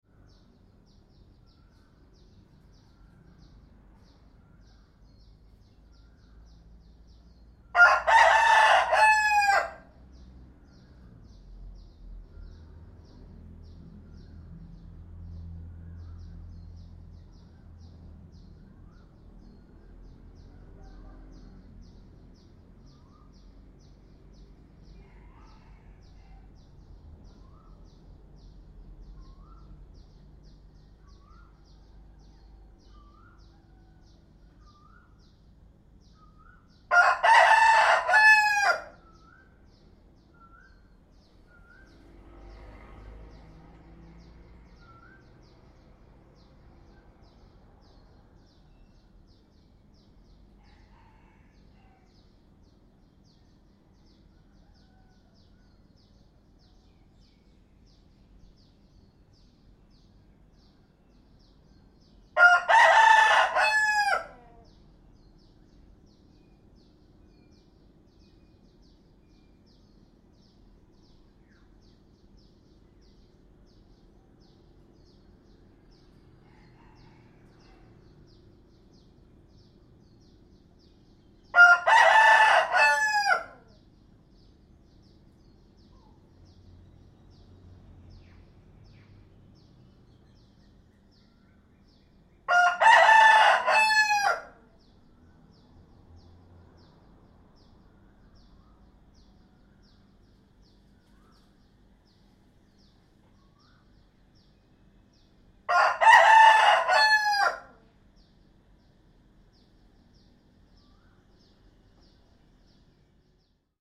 دانلود صدای خروس محلی برای یادآوری روزهای گذشته و نوستالژی از ساعد نیوز با لینک مستقیم و کیفیت بالا
جلوه های صوتی